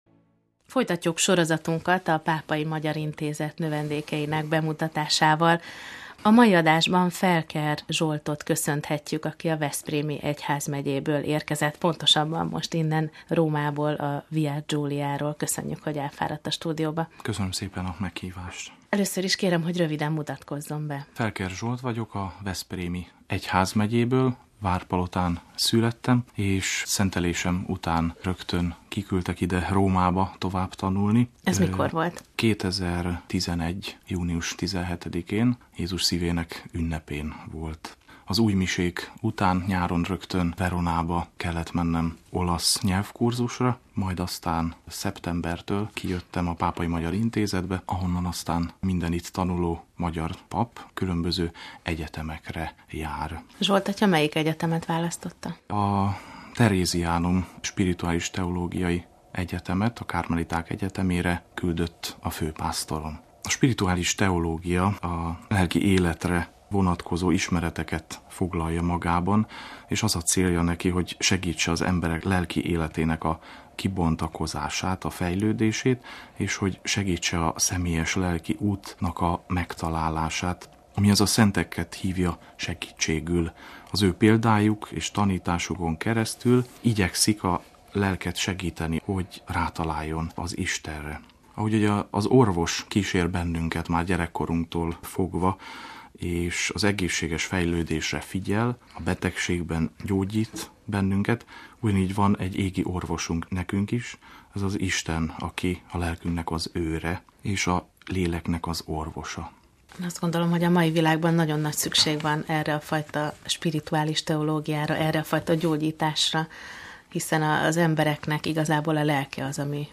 Lélekgyógyítás Istennel - Stúdióbeszélgetés